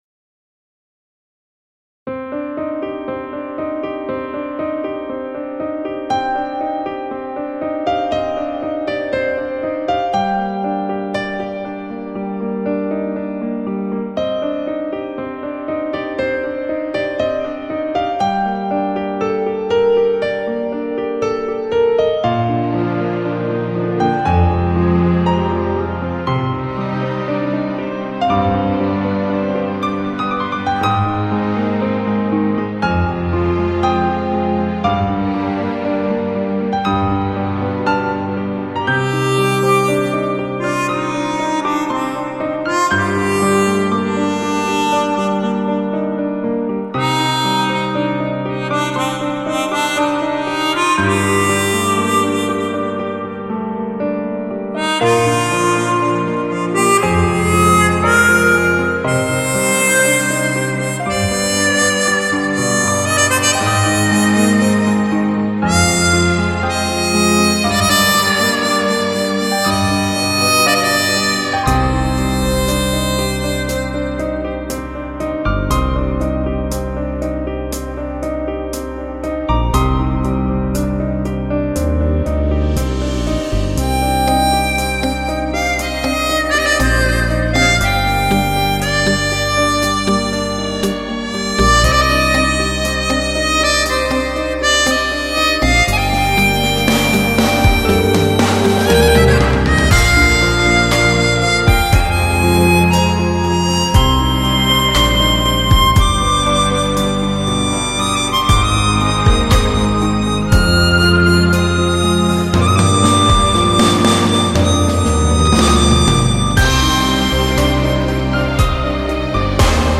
یکی از قطعات بی‌کلام بسیار مشهور و احساسی
ترکیبی از موسیقی کلاسیک، الکترونیک و نیو ایج است